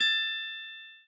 glock_last_12.ogg